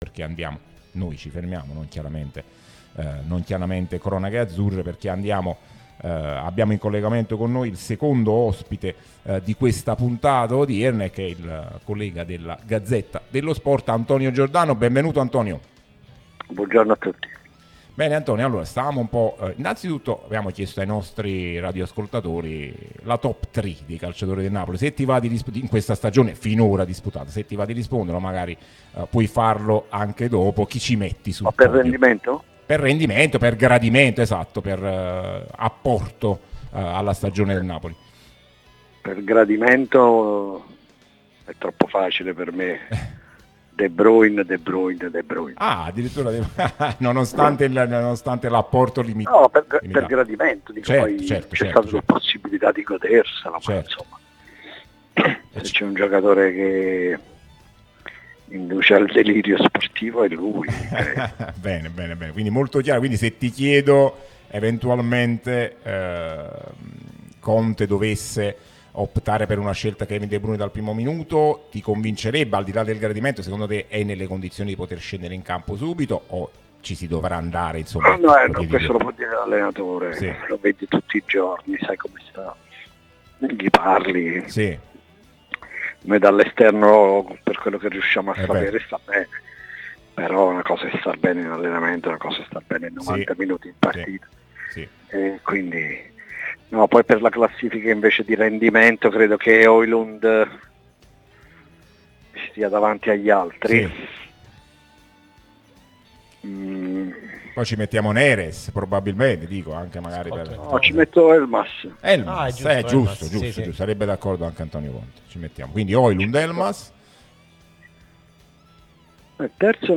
Podcast Top Tre del Napoli?